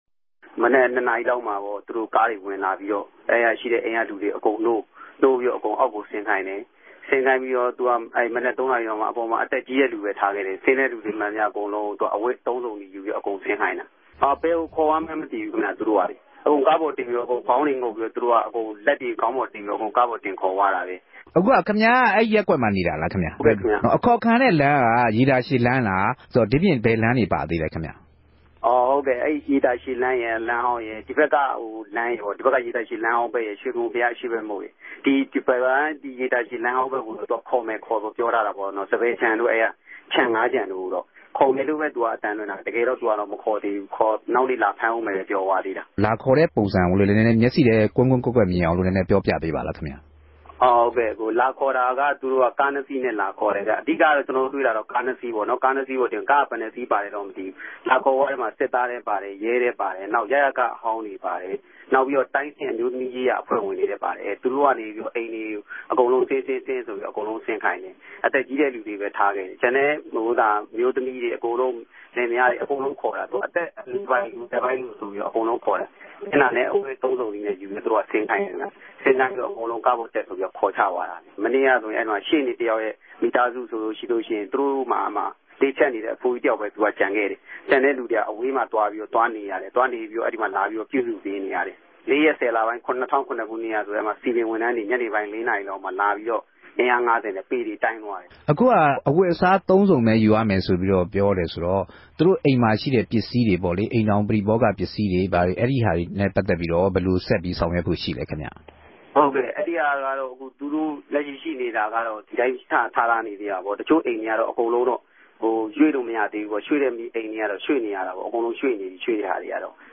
အေူပာင်းရြေႛခံရတဲ့ အိမ်ေူခ ၃၀၀ရာ လောက်ရြိတယ်လိုႛသိရပၝတယ်။ လူတေကြို ကားပေၞ တင်ခေၞသြားတာ တြေႛူမင်လိုက်ရသူ တဦးကို မေးူမန်းုကည့်တဲ့အခၝ ခုလိုေူပာပၝတယ်။